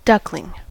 duckling: Wikimedia Commons US English Pronunciations
En-us-duckling.WAV